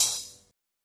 Open Hat (Rockin').wav